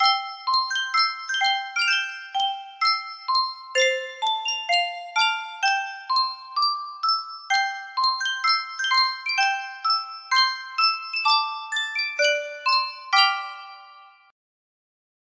Superstar Music Box - I've Been Working on the Railroad K3 music box melody
Full range 60